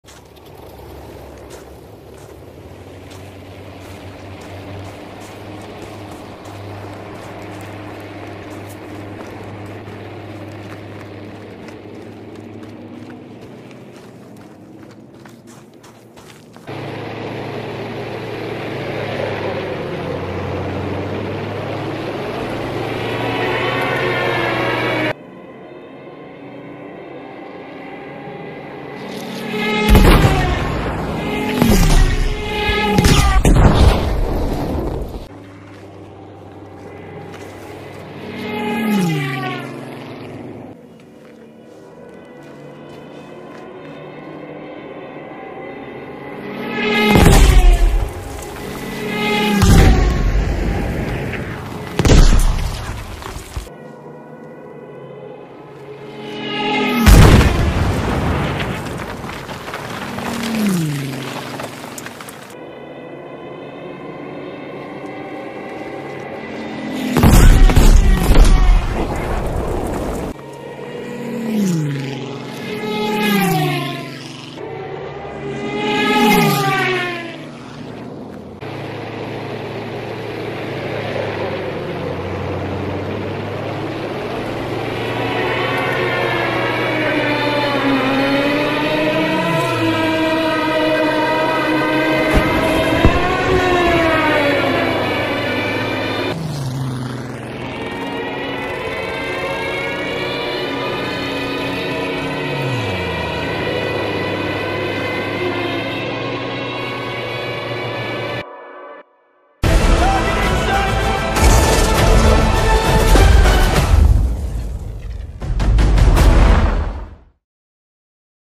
Stuka dive bomber